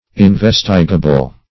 Investigable \In*ves"ti*ga*ble\, a. [L. investigabilis. See